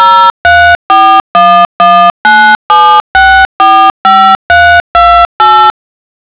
tonos.wav